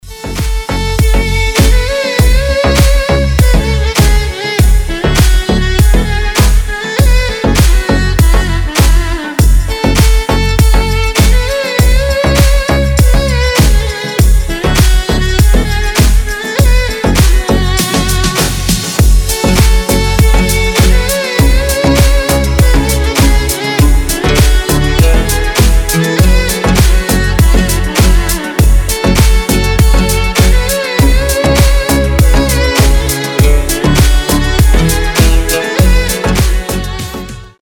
deep house
восточные мотивы
без слов
скрипка
Красивый Deep House со звуками скрипки.